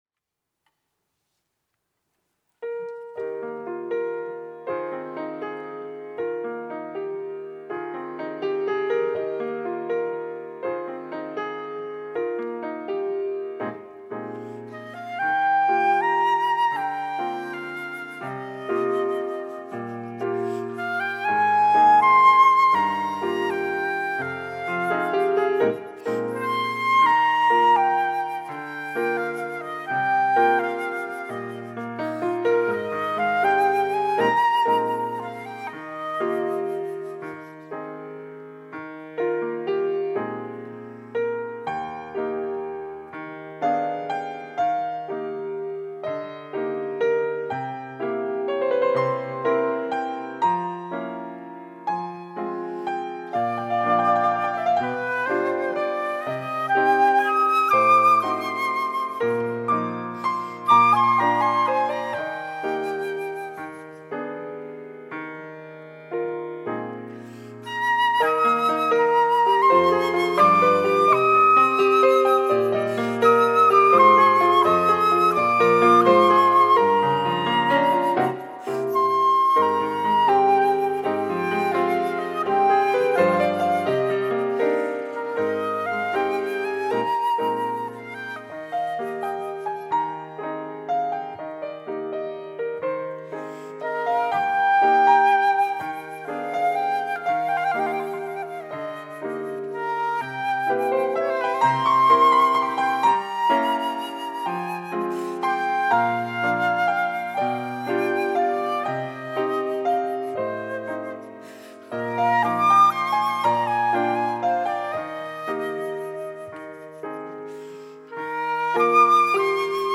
특송과 특주 - 참 아름다워라